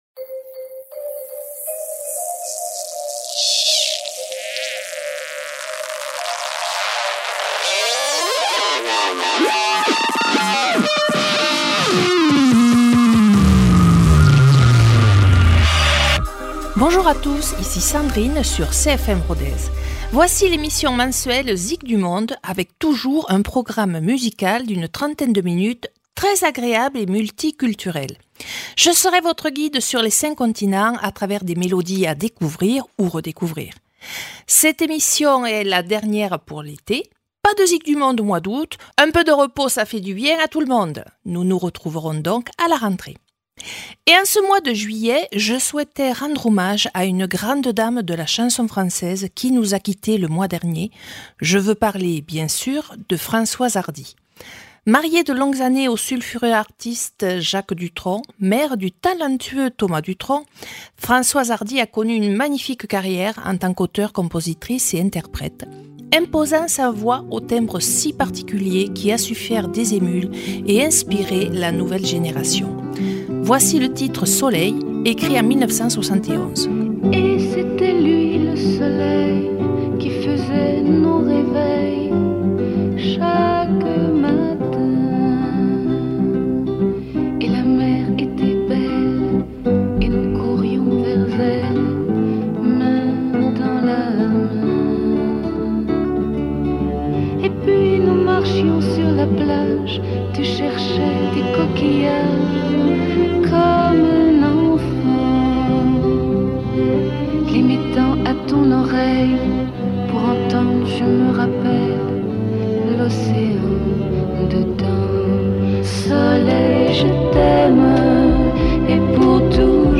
Dernière émission musicale avant les vacances d’été. Dansez, chantez aux sons de belles mélodies du monde entier.